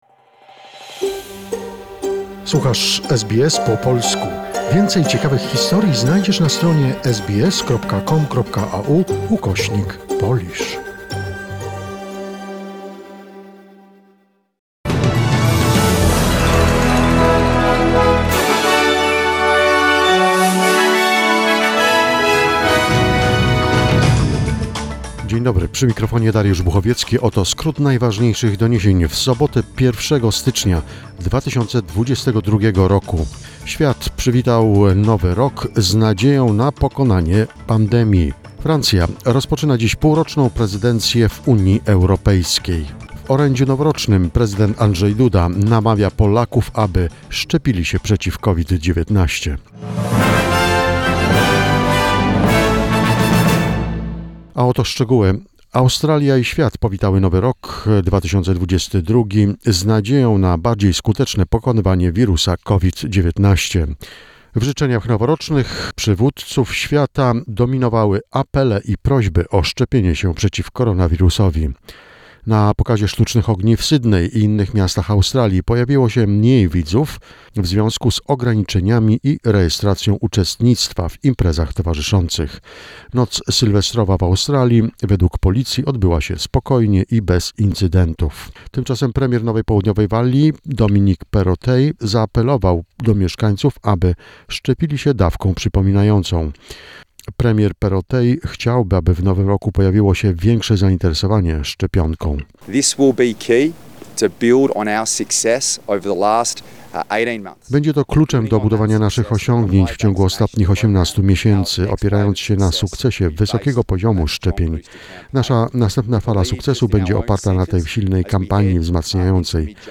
SBS News Flash in Polish, 1 January 2022